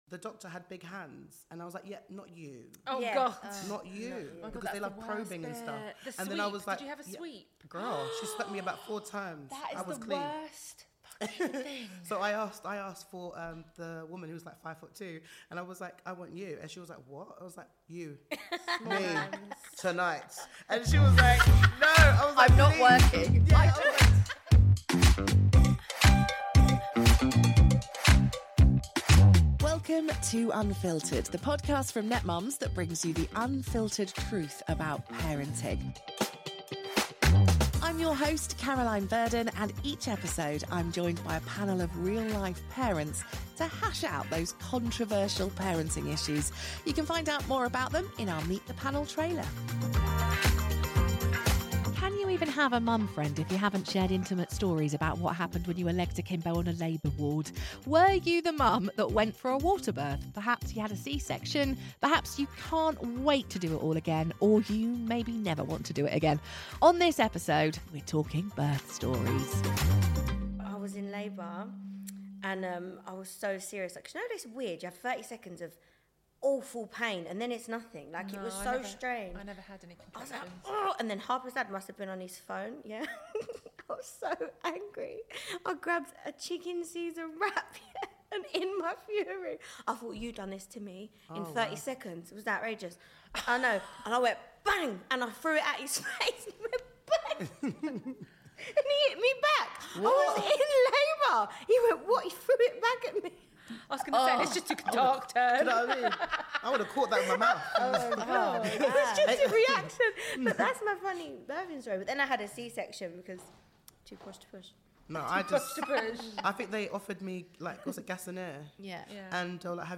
Expect laughter, empathy, and a few shocking twists as they uncover the truth about what it’s really like to bring a new life into the world.